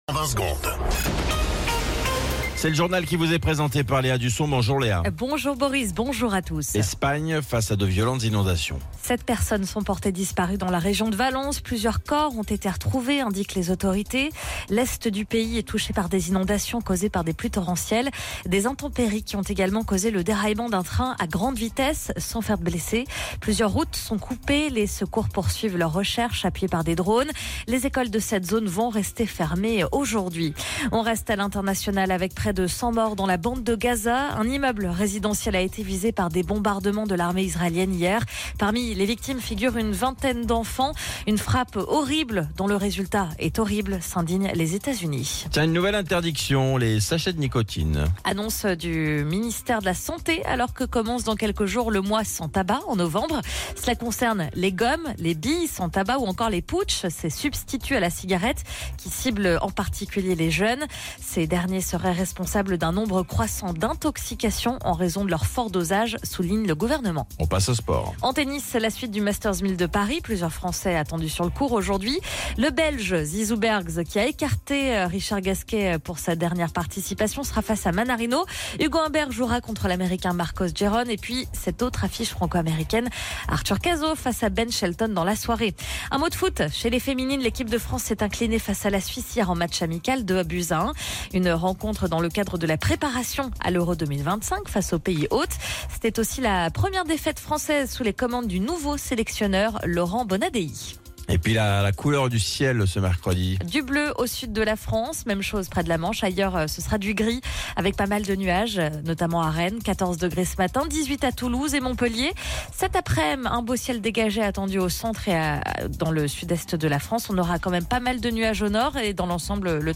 Flash Info National 30 Octobre 2024 Du 30/10/2024 à 07h10 .